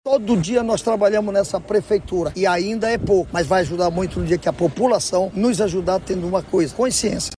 O secretário Municipal de Limpeza Urbana, Sabá Reis, destaca que a contribuição da população é essencial.